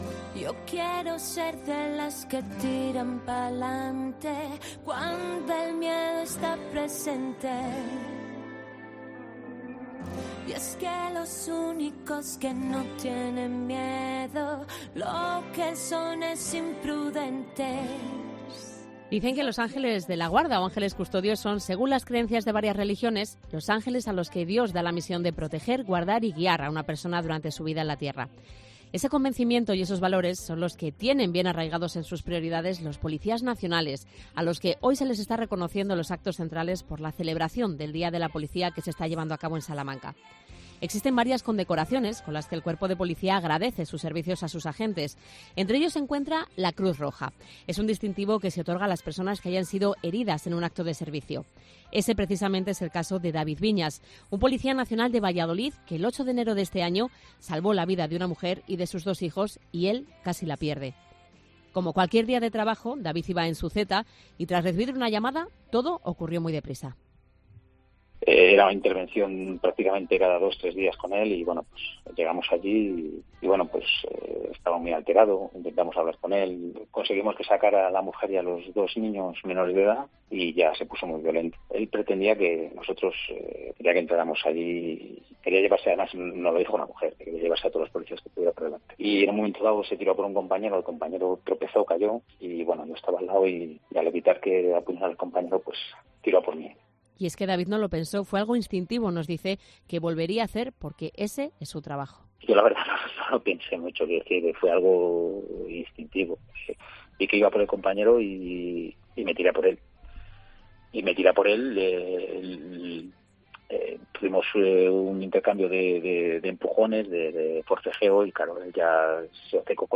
COPE Valladolid habla con dos de los agentes condecorados en el día de la Policía Nacional